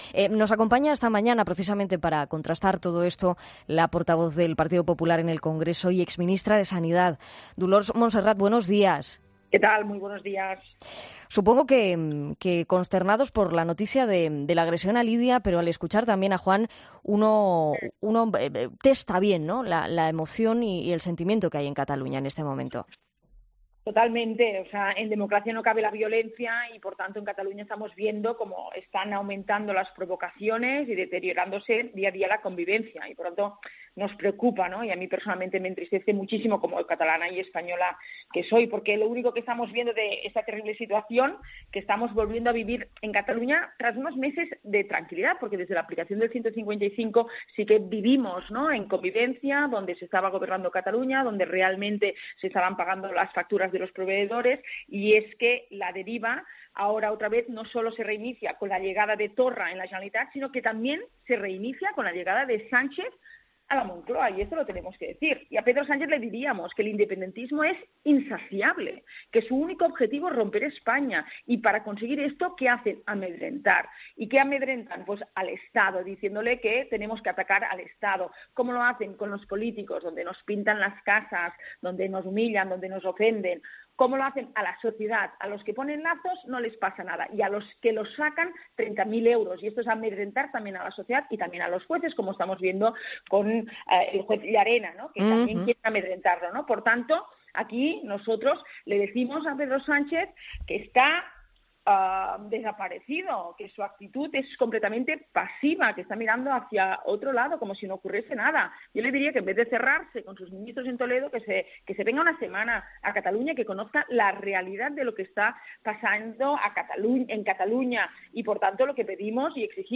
A ellos se ha sumado en 'Fin de Semana' la portavoz del PP en el Congreso, Dolors Montserrat.